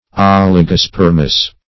Search Result for " oligospermous" : The Collaborative International Dictionary of English v.0.48: Oligospermous \Ol`i*go*sper"mous\, a. [Oligo- + Gr. spe`rma a seed.]